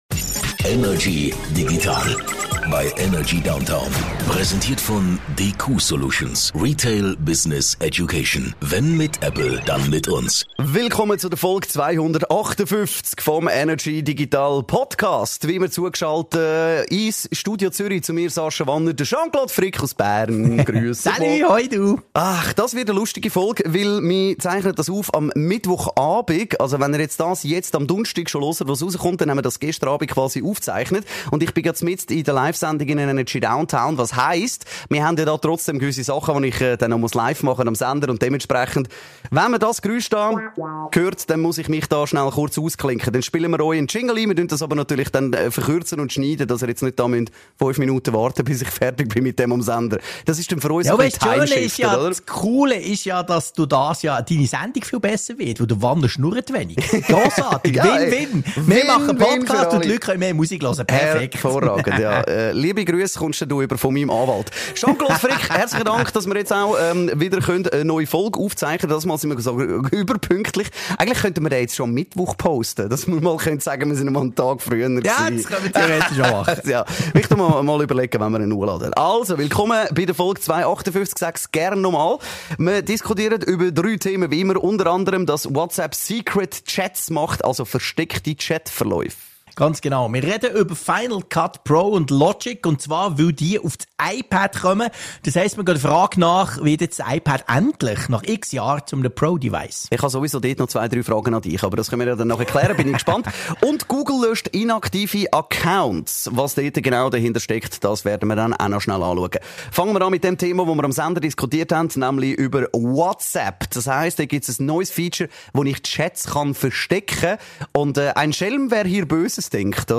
im Energy Studio
aus dem HomeOffice